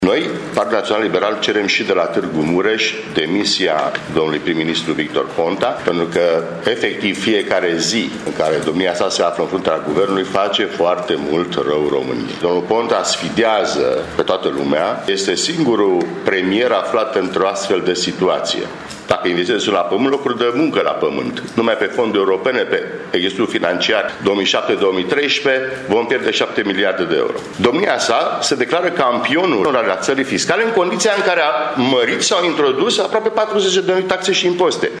La toate acestea se adaugă probleme juridice ale premierului, unice în lume, în opinia copreședintelui PNL Vasile Blaga: